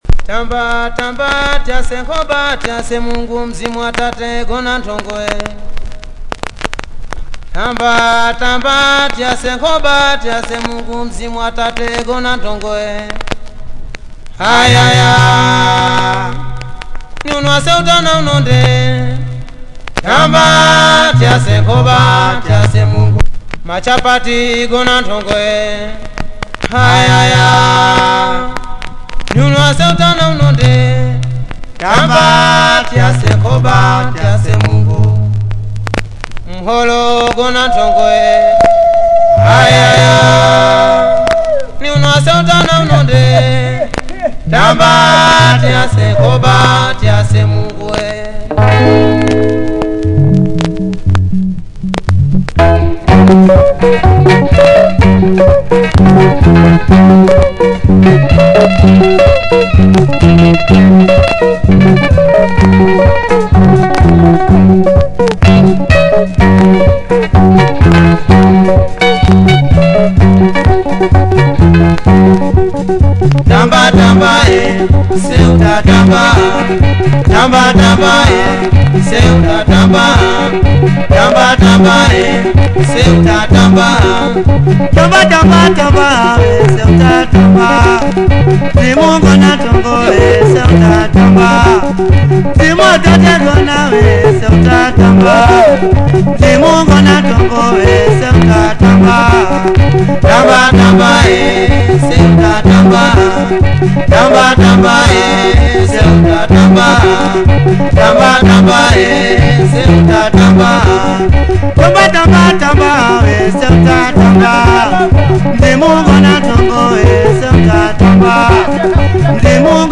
fantastic Swahili rumba blend of styles